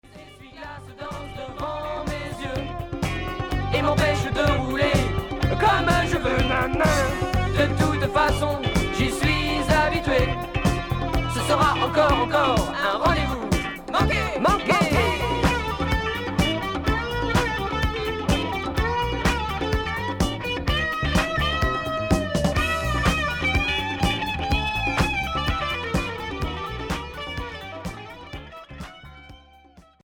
Rock funky